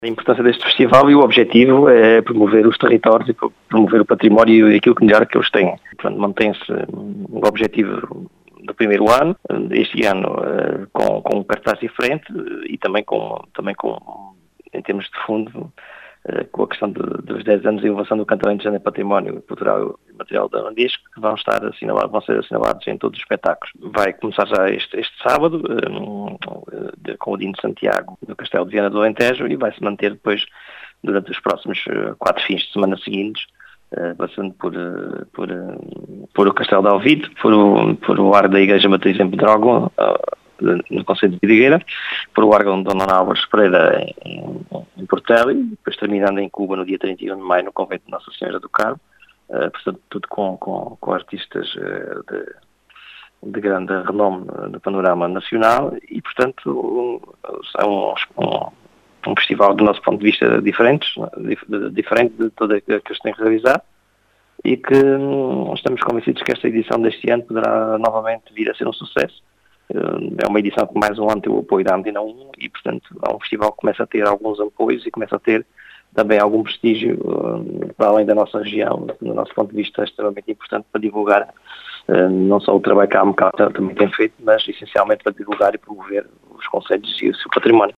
As explicações foram deixadas pelo presidente da Associação dos Municípios do Alentejo Central, João Português, que fala num Festival “diferente”, que pretende “promover os territórios e o património”.